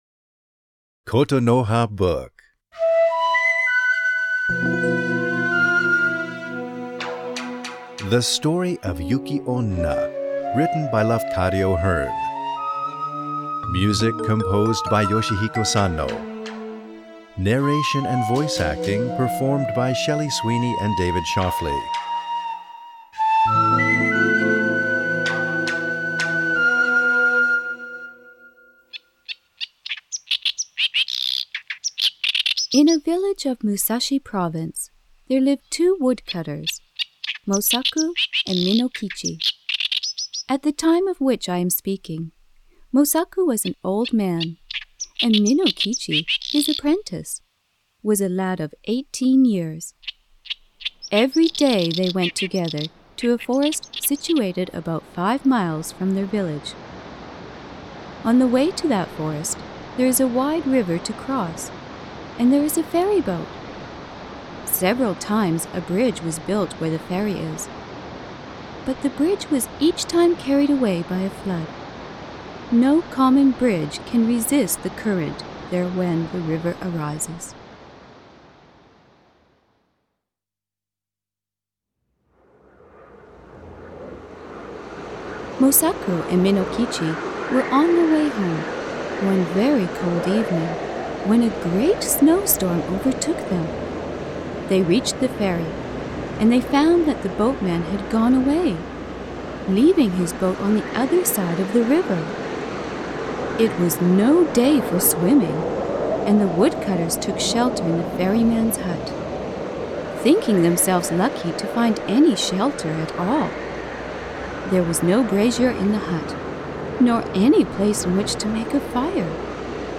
[オーディオブック] Yuki-Onna "A snow woman"
The excitement of a live performance as well as the horror of the original story and the exoticism of Japan has been fully recreated by an experienced staffs of radio dramas.